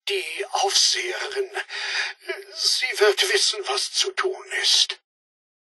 Fallout 76: Audiodialoge